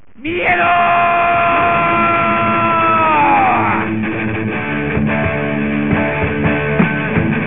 Punkarra